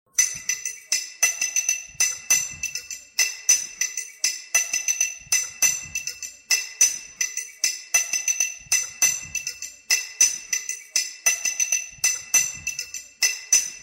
Tiếng hủ tiếu gõ trong đêm vắng Sài Gòn
Tiếng gõ của người bán hủ tiếu, mì gõ Sài Gòn Tiếng rao bán Hủ Tiếu Gõ kêu lóc cóc ngày xưa…
Thể loại: Tiếng động
Description: Tiếng hủ tiếu gõ trong đêm vắng Sài Gòn – tiếng “lóc cóc”, “leng keng”, “cốc cốc” vang vọng giữa con hẻm tĩnh mịch, gợi nhớ ký ức xưa cũ và hơi thở đời thường.
tieng-hu-tieu-go-trong-dem-vang-sai-gon-www_tiengdong_com.mp3